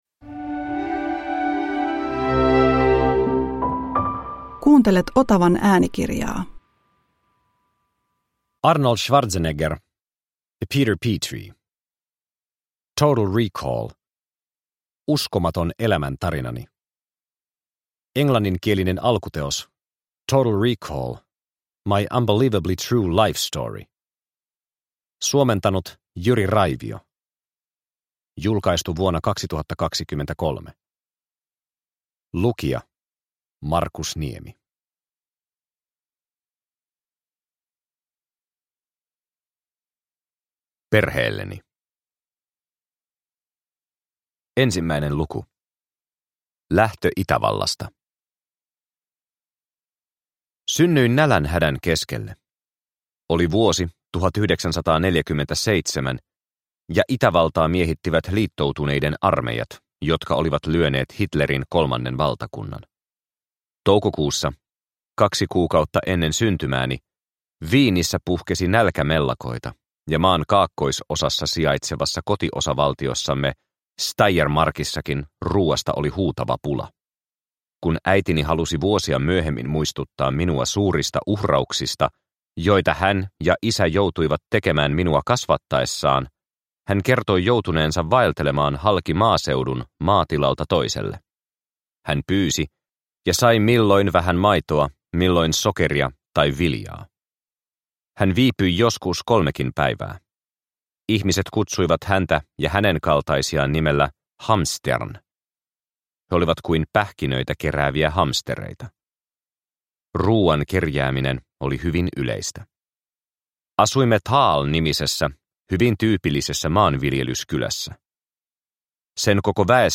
Total Recall – Ljudbok – Laddas ner